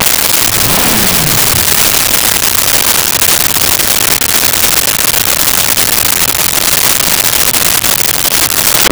Speed Bike Start Rev Off
Speed Bike Start Rev Off.wav